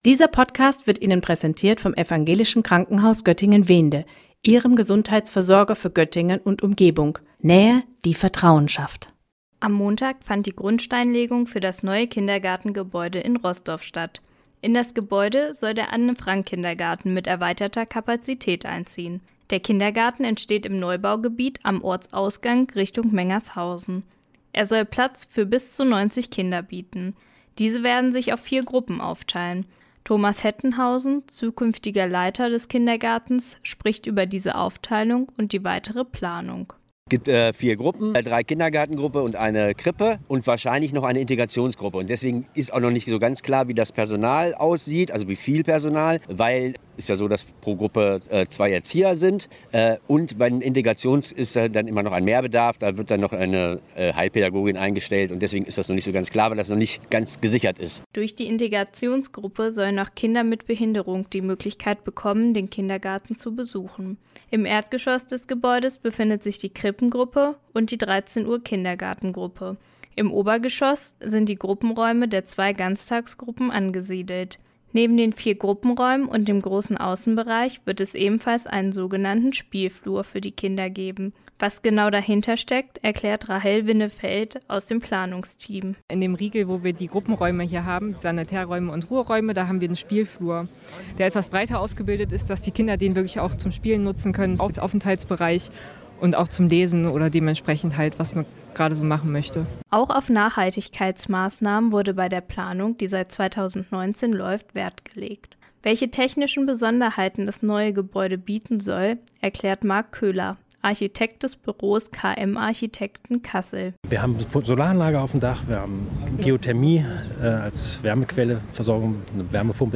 Der Anne-Frank-Kindergarten in Rosdorf bekommt ein neues Kindergartengebäude. Die Grundsteinlegung fand am Montag statt.